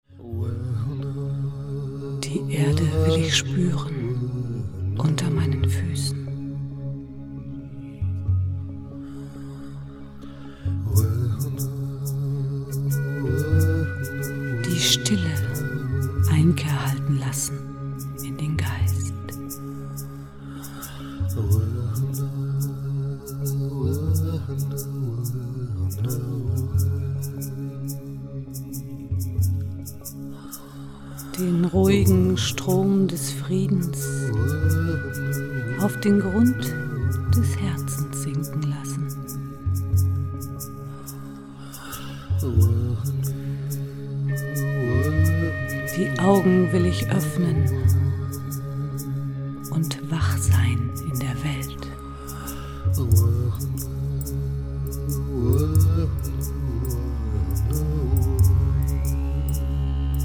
Musik & Poesie aus der Stille
Piano, Gesang und Flöten
warme, berührende Stimme